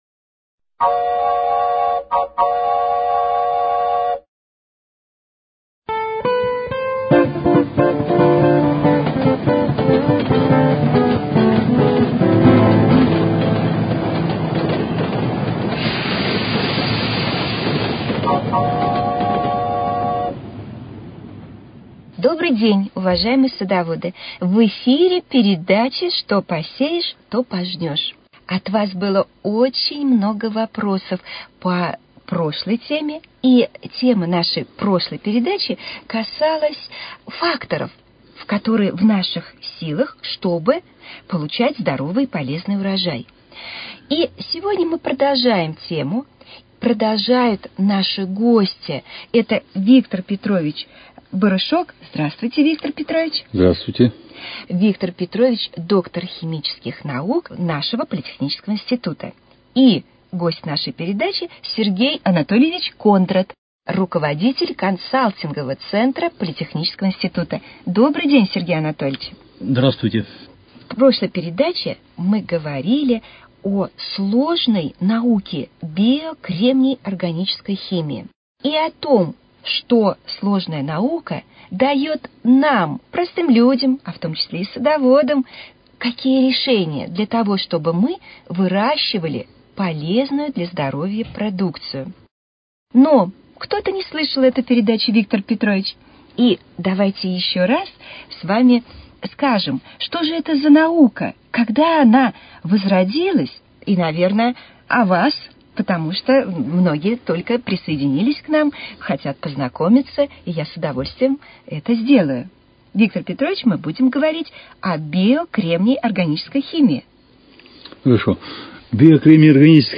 Цикл передач